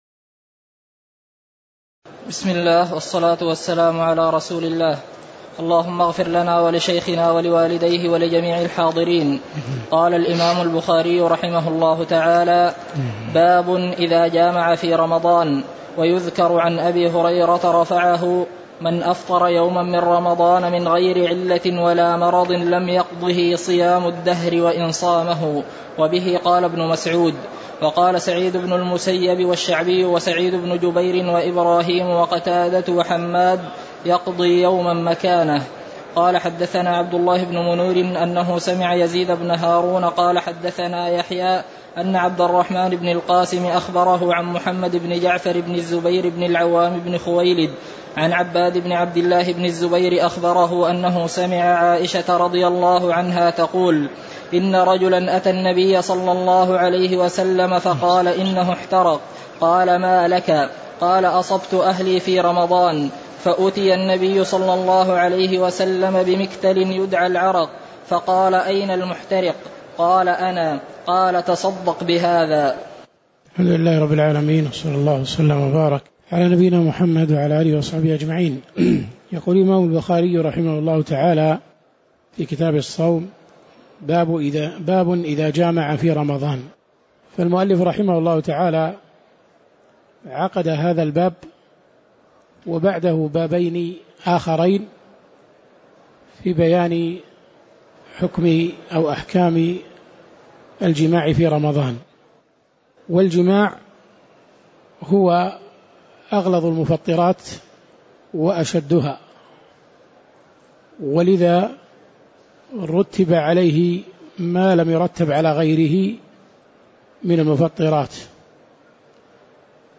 تاريخ النشر ٩ رمضان ١٤٣٨ هـ المكان: المسجد النبوي الشيخ